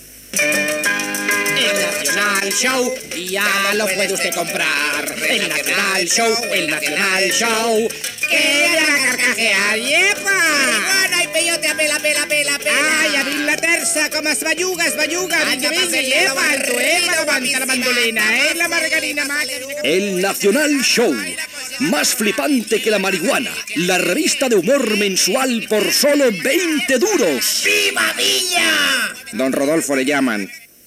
Anunci de la revista "Nacional Show".